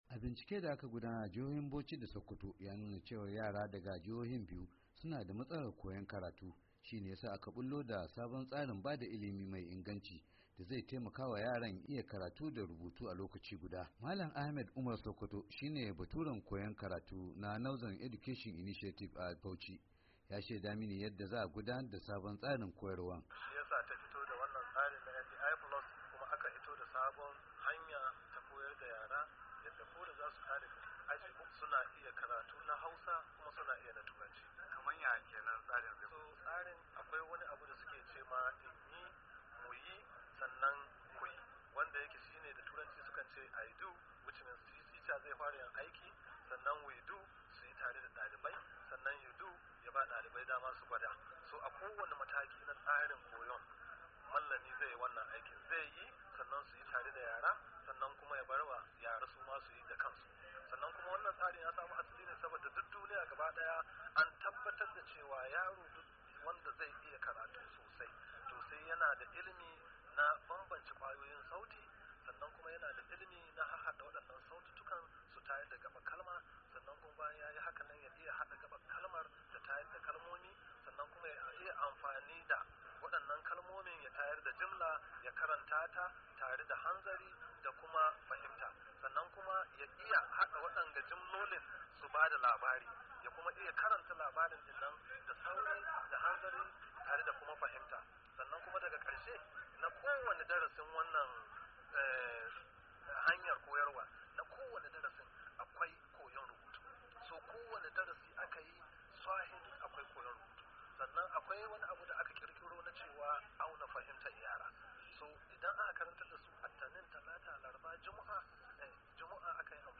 Gwamnan jihar Barrister M.A. Abubakar yace an samar da kudi kashi talatin cikin dari na ilimi kamar yadda yake a kasafin kudin jihar.